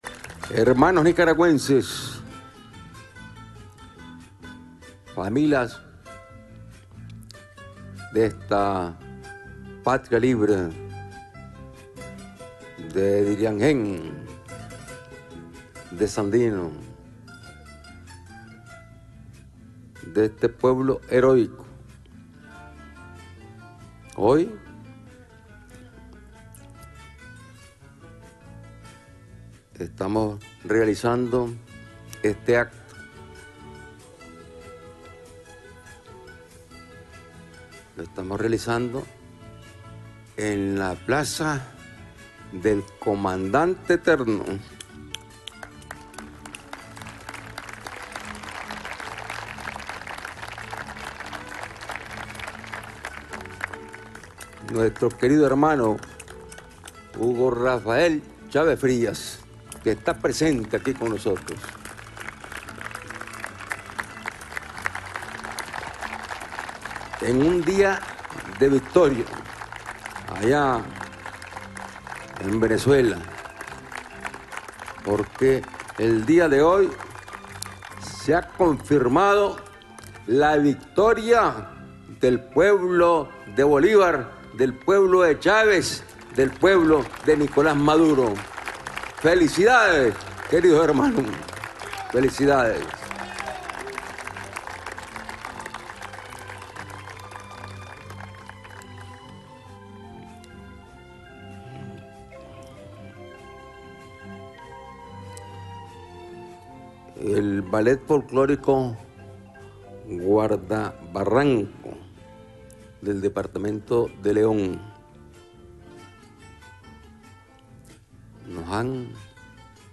Durante el acto de entrega de buses chinos, recordó que a partir del año 2007 que asumió nuevamente el Gobierno Sandinista, el pueblo unido ha venido alcanzado más victorias.
Audio del discurso del Presidente Daniel Ortega (descargar o escuchar en línea)